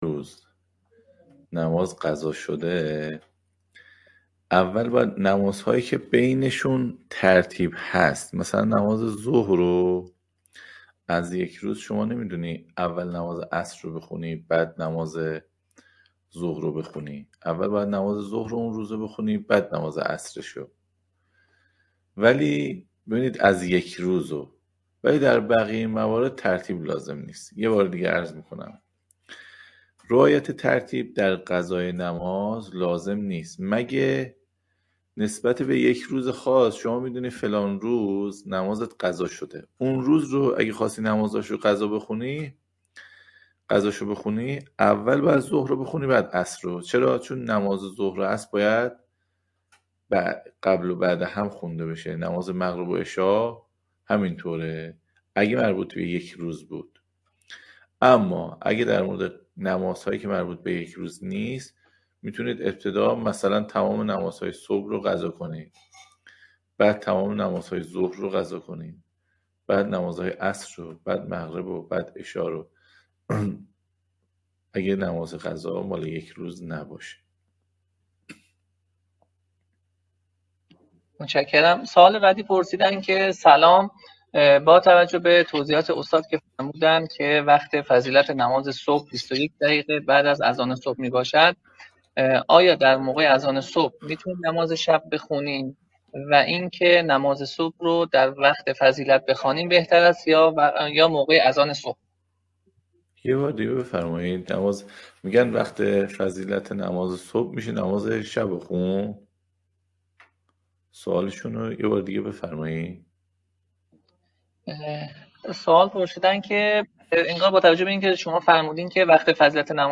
پایه‌ نیایش (احکام عبادات) - جلسه-پرسش-و-پاسخ